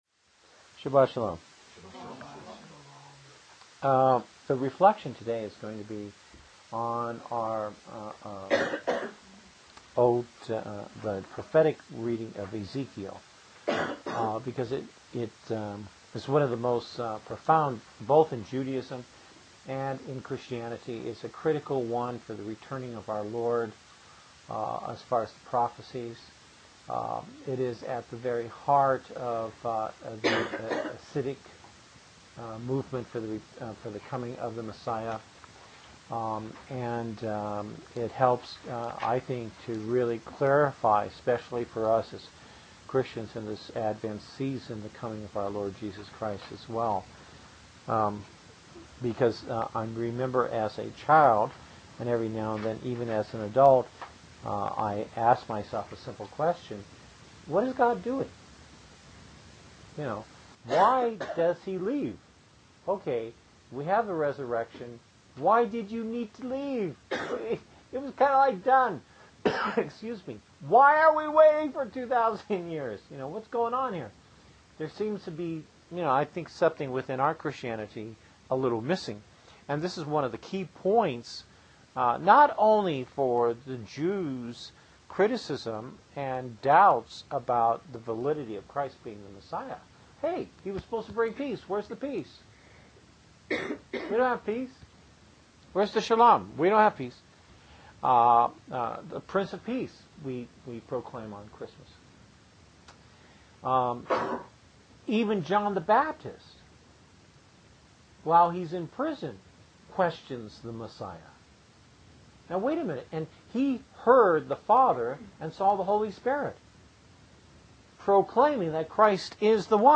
Passage: Ezekiel 37:1-28 Service Type: Christian Shabbat Service %todo_render% « Gifts from the Heart Do You Hear What I Hear?